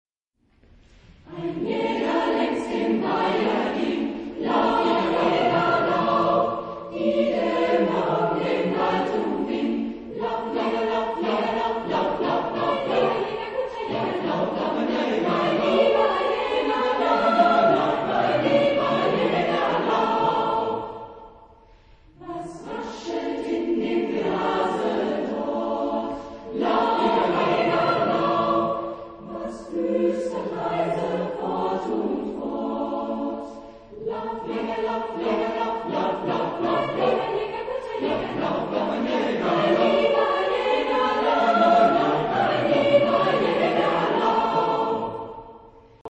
Genre-Style-Form: Folk music ; Partsong ; Secular
Type of Choir: SSAATBB  (7 mixed voices )
Soloist(s): Sopran (1)  (1 soloist(s))
Tonality: A major
Discographic ref. : 7. Deutscher Chorwettbewerb 2006 Kiel